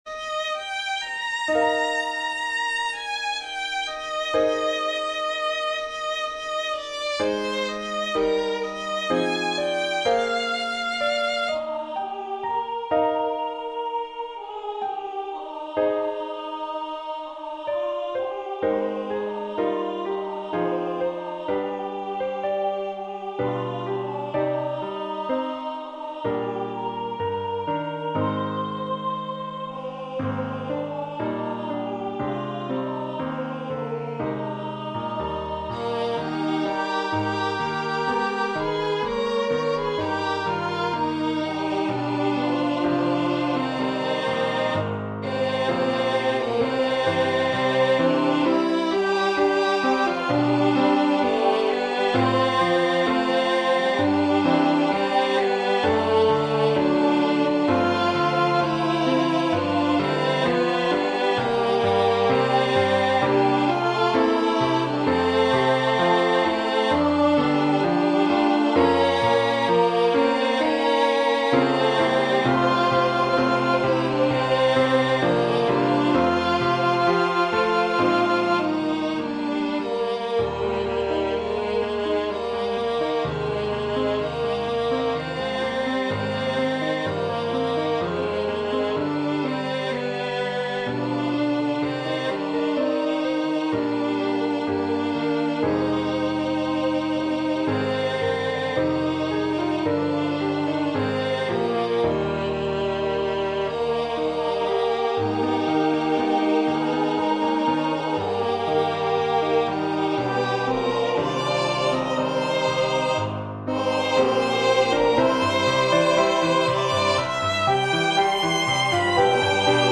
SATB with Piano & Violin Accompaniment.